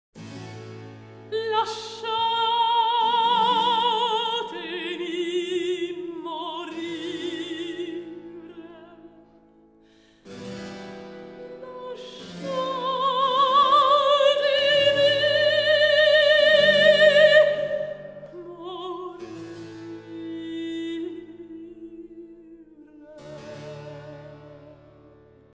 Hier is sprake van dodelijke droefenis.
Een lamento is een klaagzang